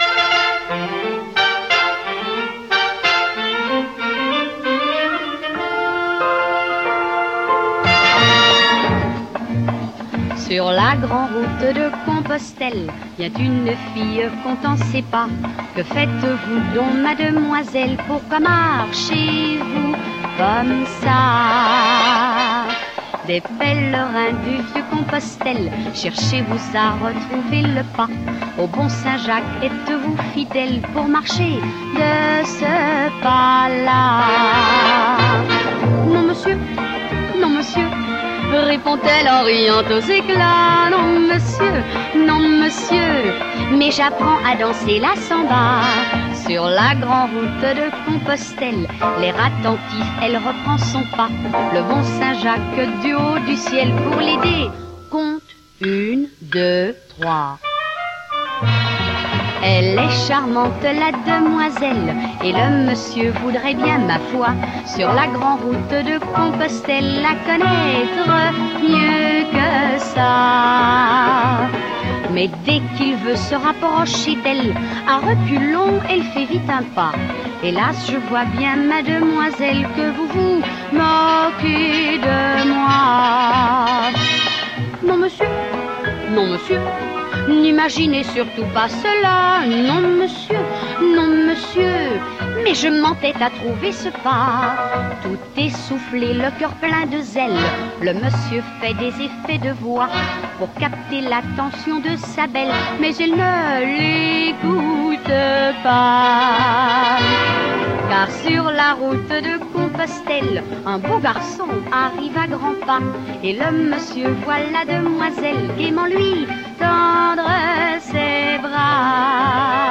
le rythme de la samba —trois pas en deux temps—